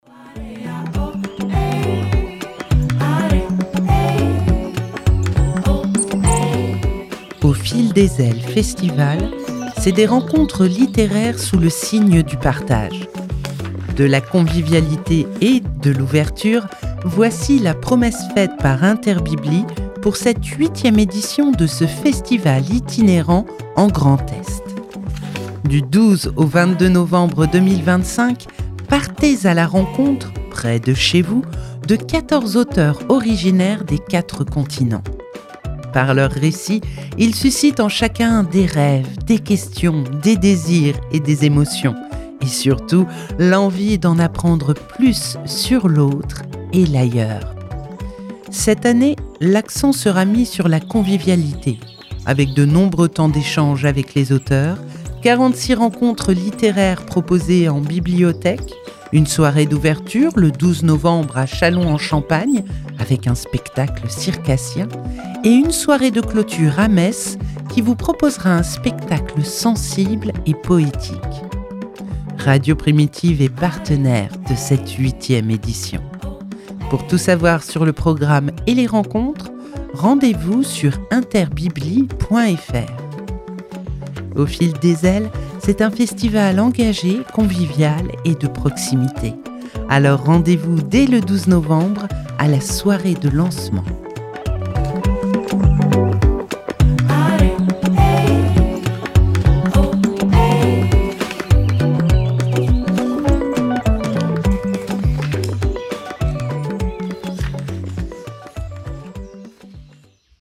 Jingle (1:50)